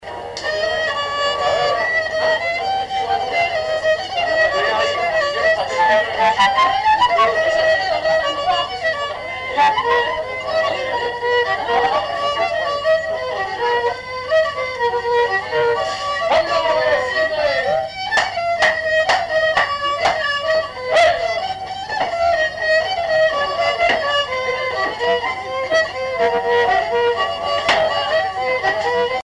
danse : branle : avant-deux
Répertoire d'un bal folk par de jeunes musiciens locaux
Pièce musicale inédite